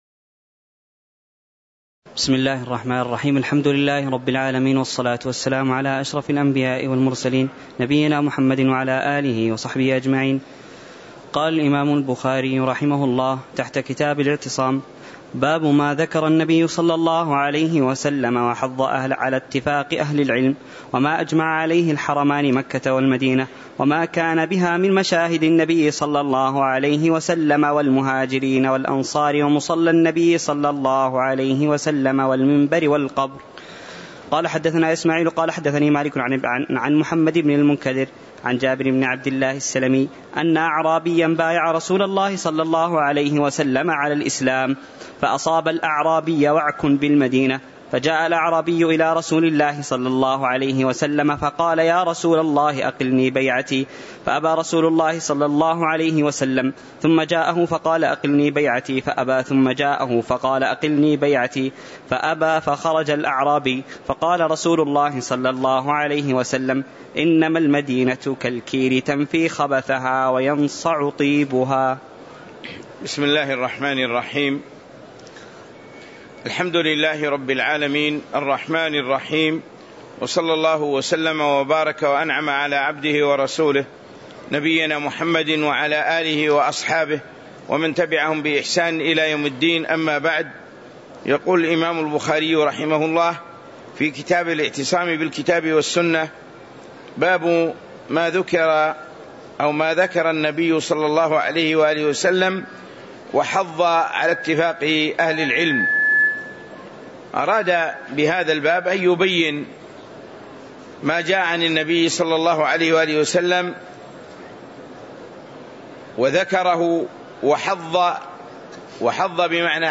تاريخ النشر ٢٣ جمادى الآخرة ١٤٤٦ هـ المكان: المسجد النبوي الشيخ